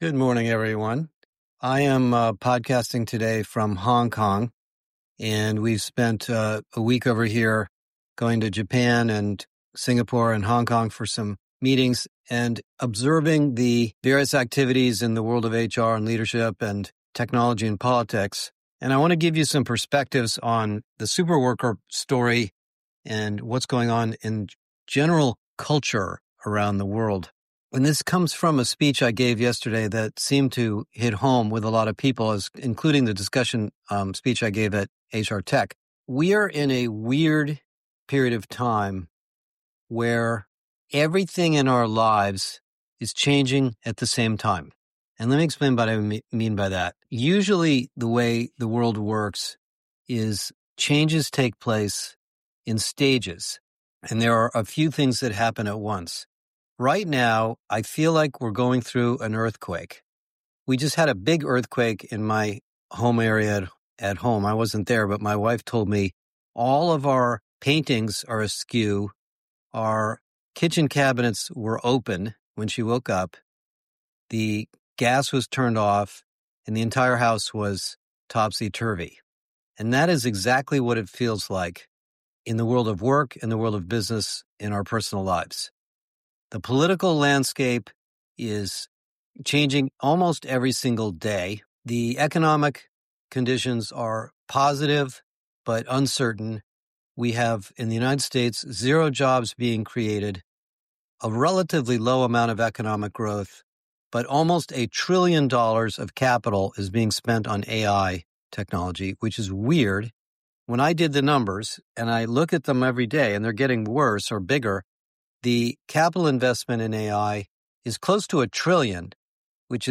Today, broadcasting from Asia, I discuss the wide range of disrupting change hitting our companies, employees, and leaders and the implications on management.